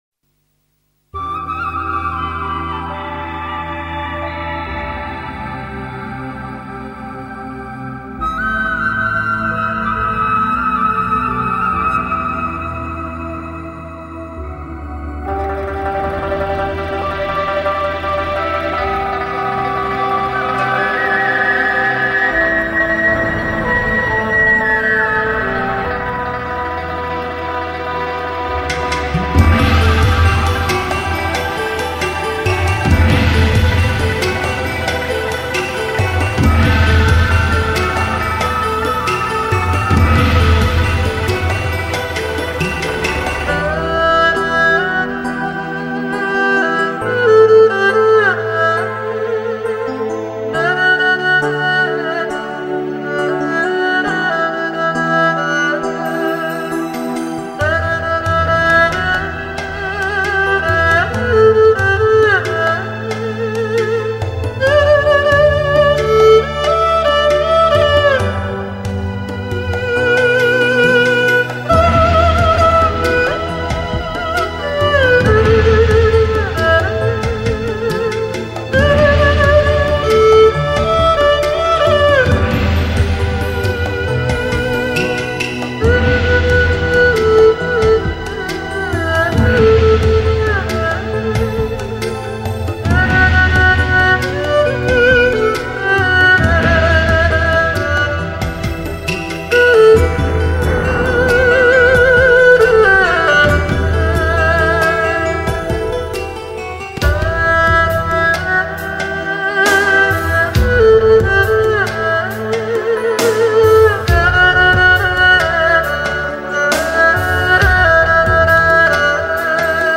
专辑格式：DTS-CD-5.1声道